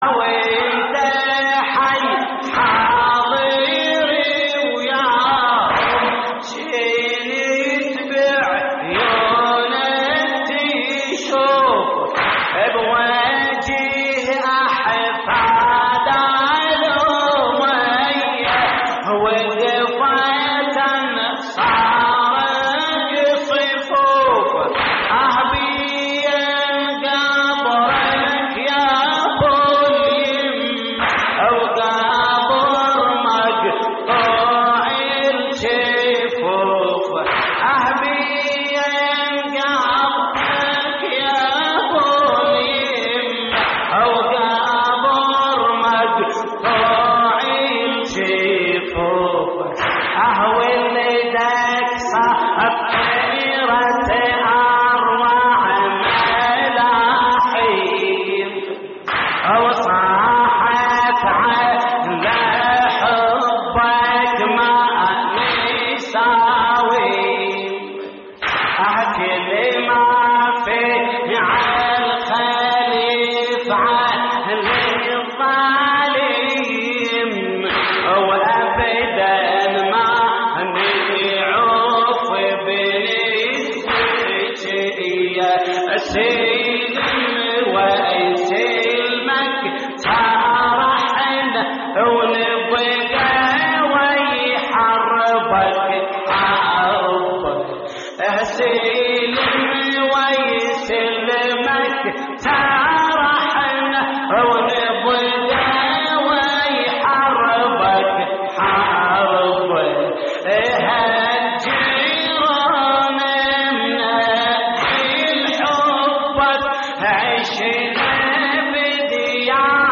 تحميل : وانت حي حاضر وياهم كنت بعيونك تشوف / الرادود جليل الكربلائي / اللطميات الحسينية / موقع يا حسين